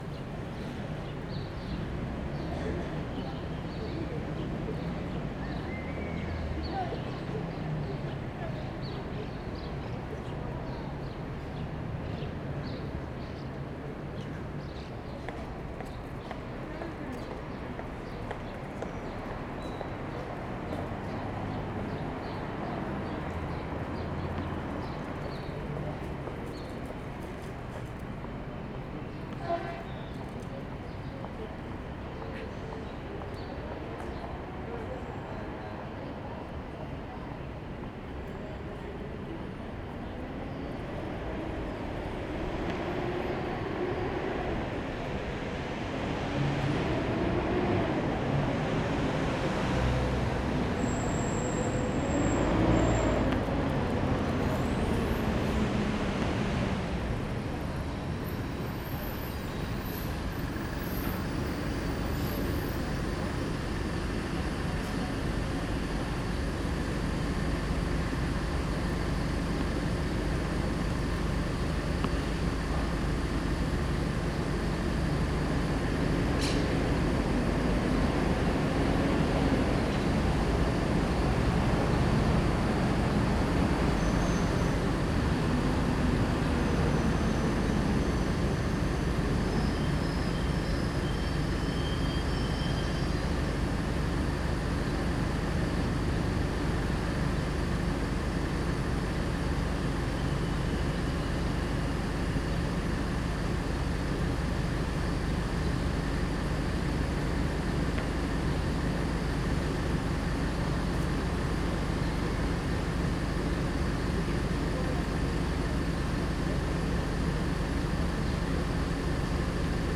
Bartolomé Mitre 3050 17 hs. 03 de Septiembre 2022
CABA, Balvaneda, Pasaje de los Pibes de Cromañón
esa-caba-balvaneda-pasaje-de-los-pibes-de-cromanon.mp3